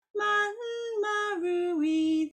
In English we don’t give “n” a separate pitch but use it to move from one vowel to another but here you’ll see that the pitch goes up on the “n” of manmaru-i :